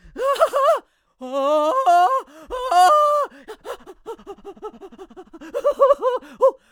traf_screams3.wav